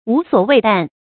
無所畏憚 注音： ㄨˊ ㄙㄨㄛˇ ㄨㄟˋ ㄉㄢˋ 讀音讀法： 意思解釋： 什么都不懼怕；什么都不顧忌。